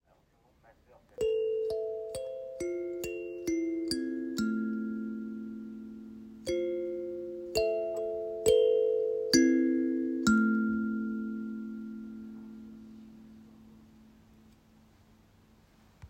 Kalimba 9 blades
• Number of tones: 9
• Chord: la', do", do', la', la, fa', mi', mi", si'
• Key: A minor
Its crystal clear tones make it a must-have for those who want to play an intuitive musical instrument.
Handcrafted in Germany from solid American cherry, its tones are magnificent with an A minor tuning for a totally intuitive and free playing.